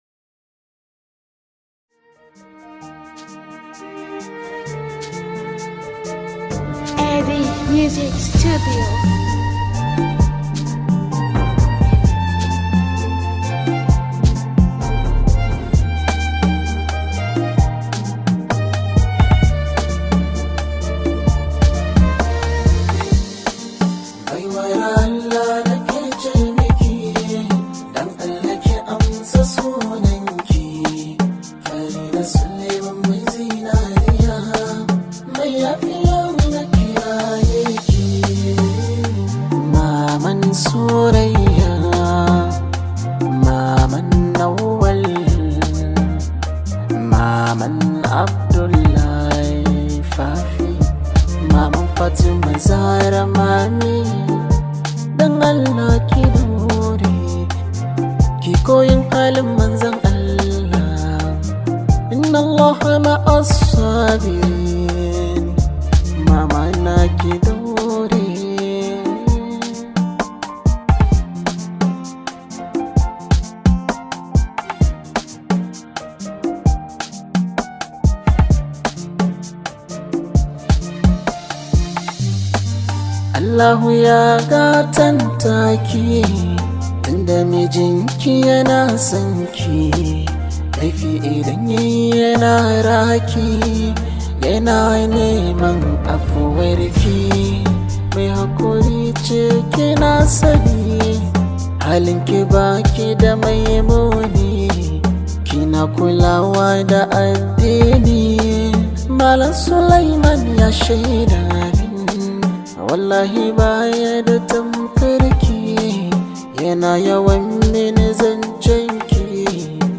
hausa music track
Arewa rooted song